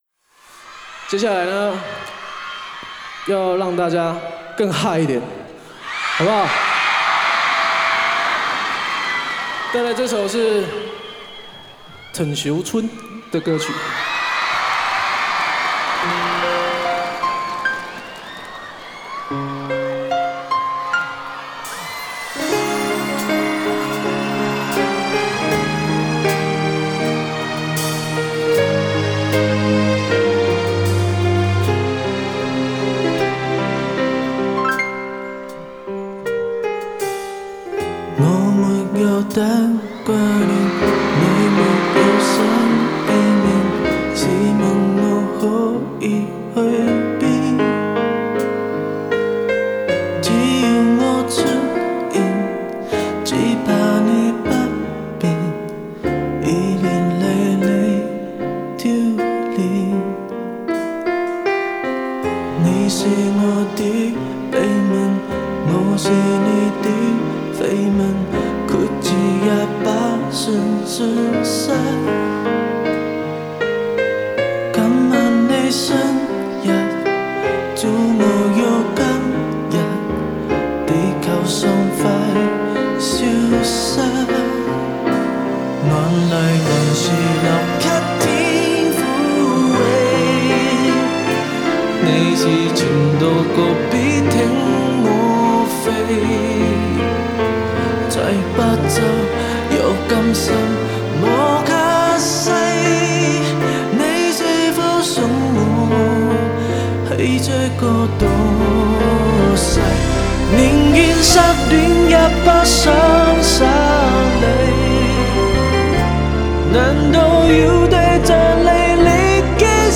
Ps：在线试听为压缩音质节选，体验无损音质请下载完整版
吉他
贝斯
鼓
键盘
打击乐